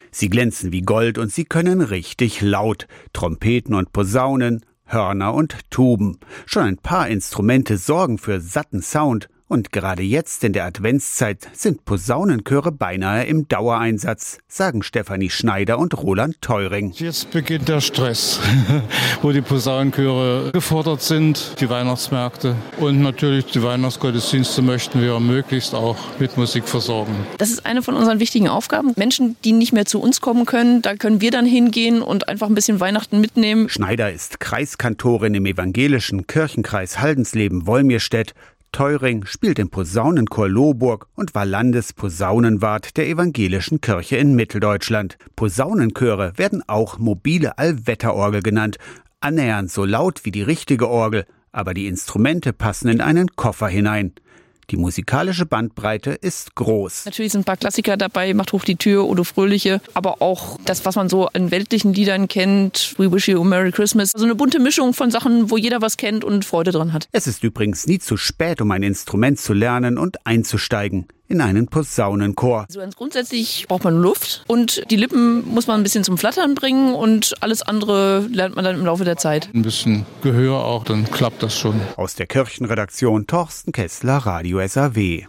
Sie glänzen wie Gold und können richtig laut: Trompeten und Posaunen. Hörner und Tuben.
iad-radio-saw-posaunenchoere-winterliche-mobile-allwetterorgel-44098.mp3